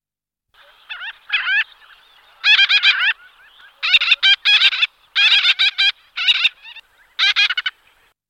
Gelochelidon nilotica